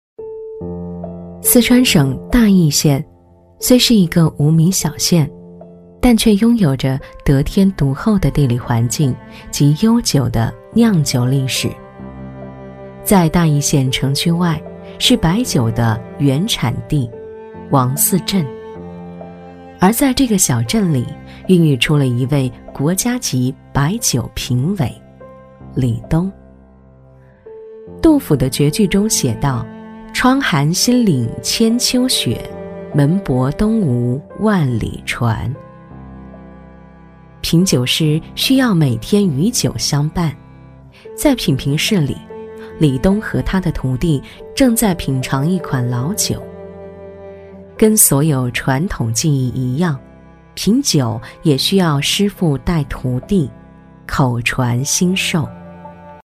茶酒文化宣传配音【海豚配音】
女45-纪录片